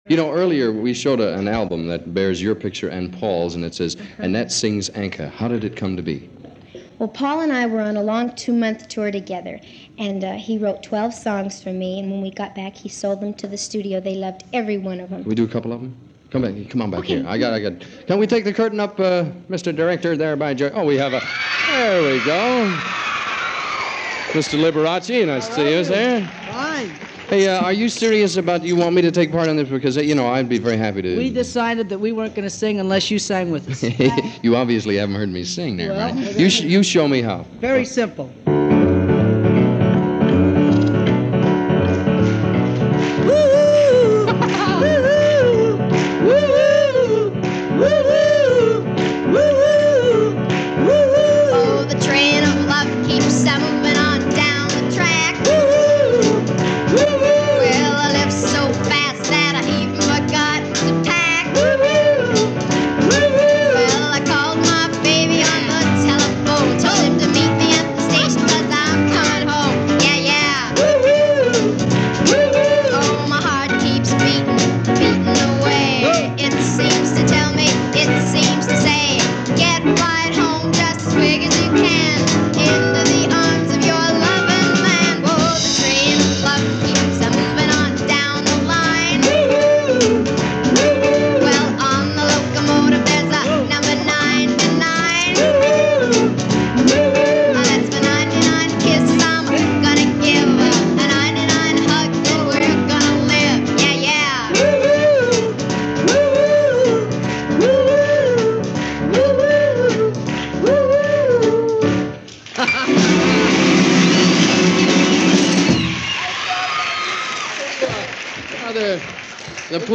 But as a small reminder – I found this audio clip from a Dick Clark Beechnut Show, airing in 1960 which features Annette and Paul Anka along with Dick Clark, breaking into impromptu song. It’s one of those unscripted moments that sum up the innocent charm of  the period and the disarming likability of Annette Funicello.